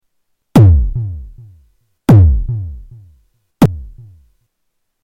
AirBase 99 bassdrum 4
Category: Sound FX   Right: Personal